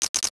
NOTIFICATION_Rattle_08_mono.wav